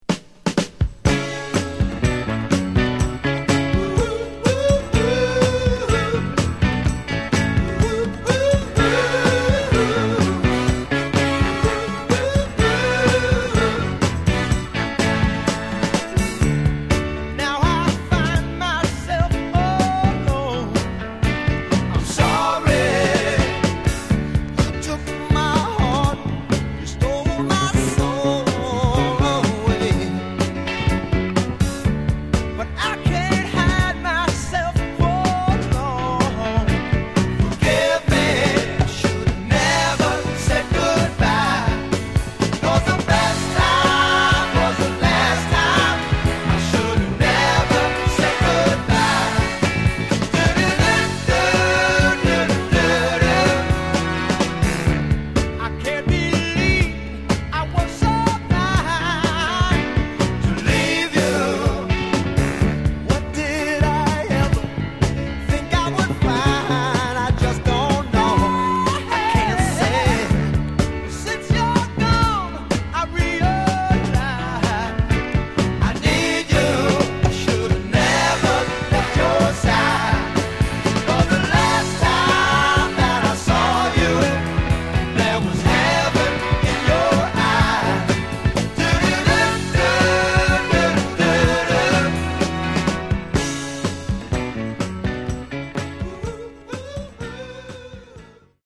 Genre: Northern Soul, Philly Style